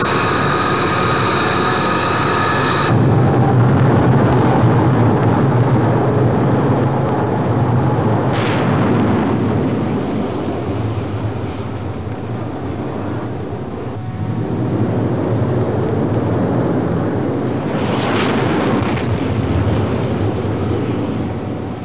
دانلود آهنگ طیاره 57 از افکت صوتی حمل و نقل
دانلود صدای طیاره 57 از ساعد نیوز با لینک مستقیم و کیفیت بالا
جلوه های صوتی